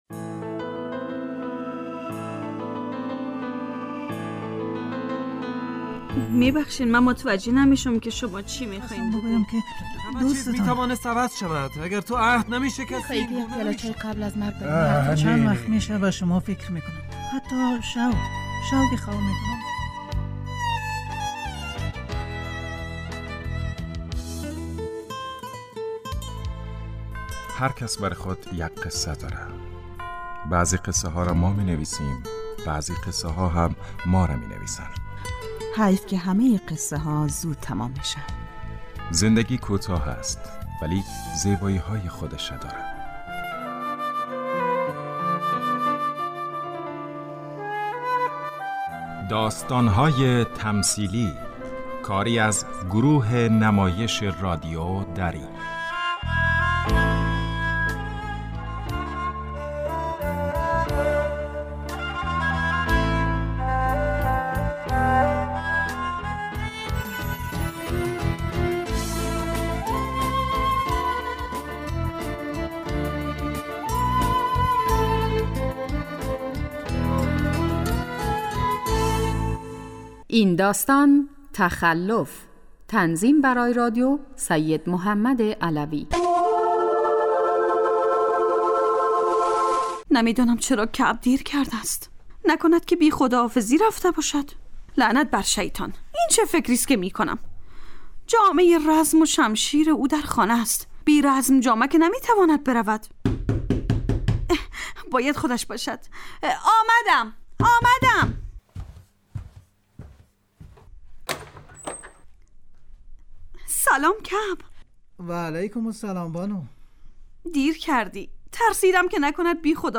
داستان تمثیلی / تخلف